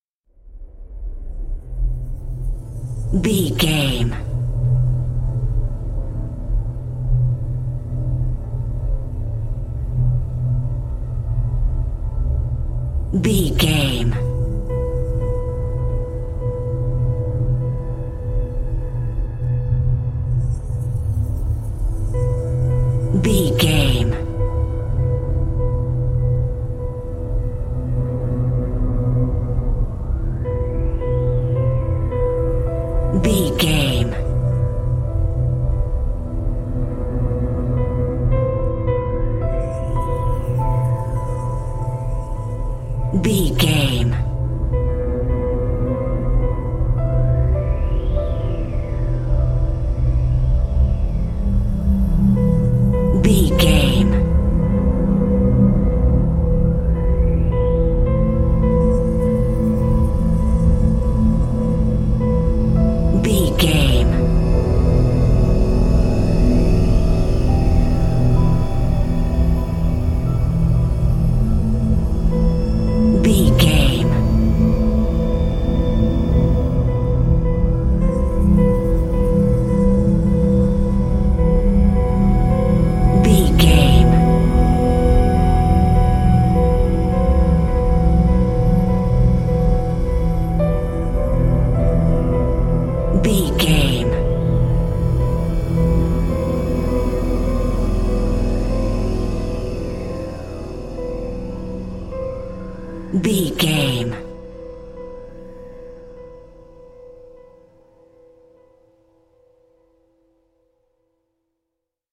Thriller
Aeolian/Minor
Slow
piano
synthesiser
electric piano
ominous
suspense
haunting
creepy